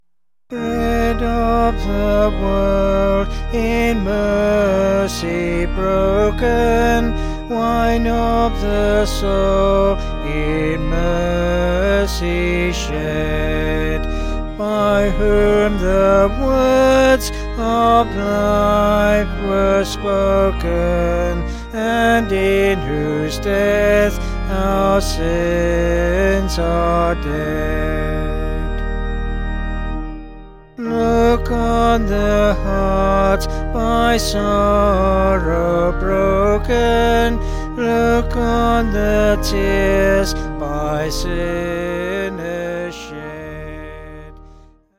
(BH)   2/Eb
Vocals and Organ